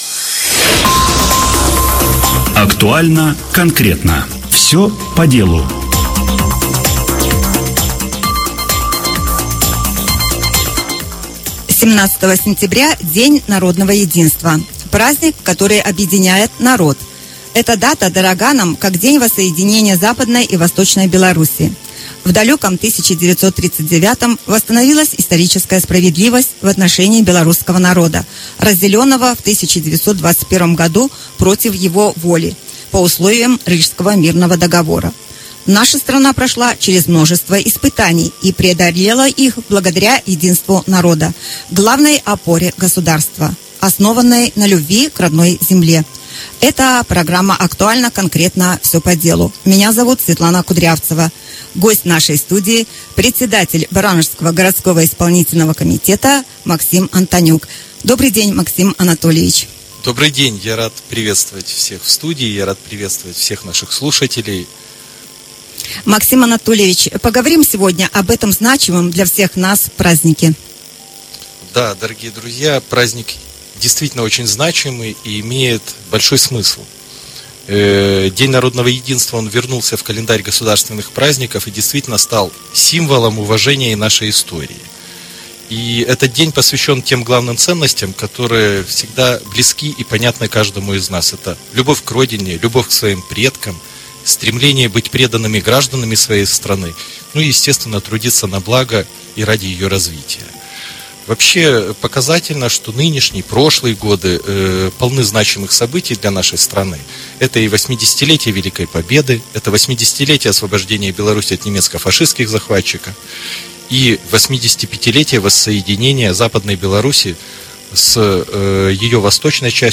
Наш разговор с председателем Барановичского городского исполнительного комитета Максимом Антонюком.